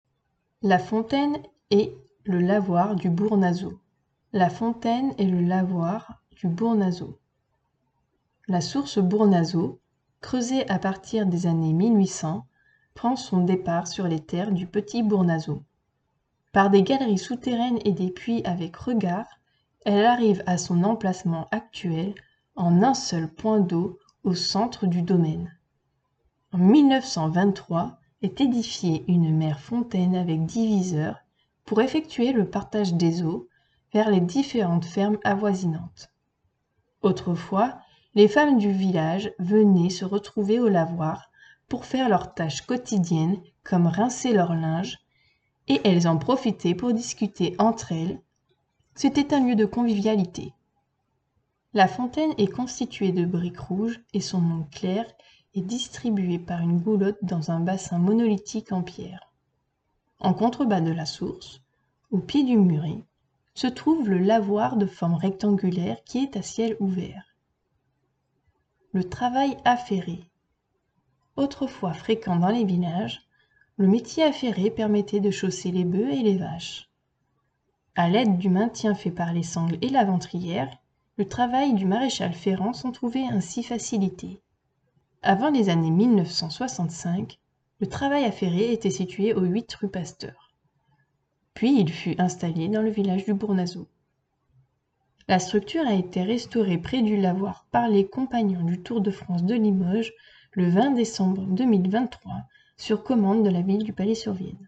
Lecture audio du panneau ici.